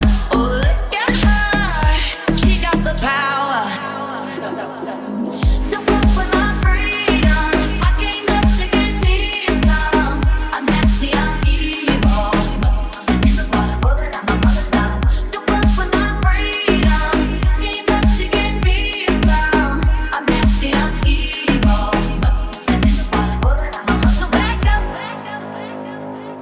громкие
deep house